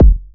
fdghs_kick.wav